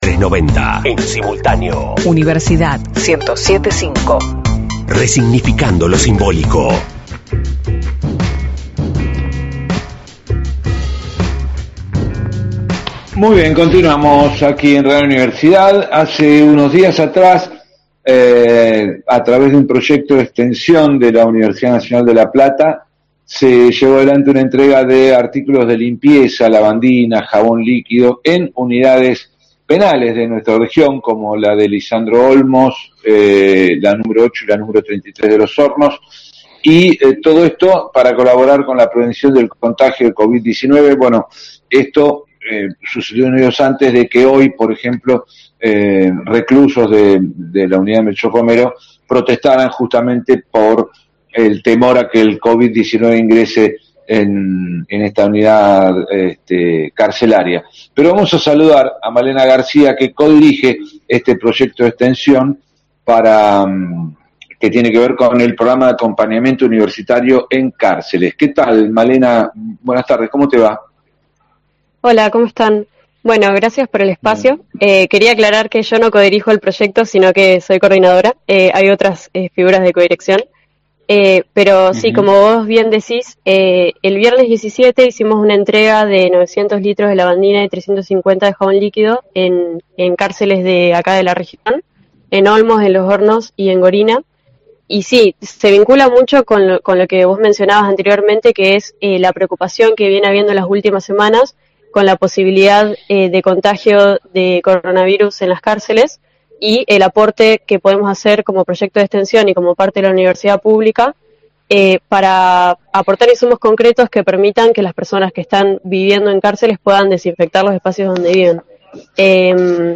La entrevista completa: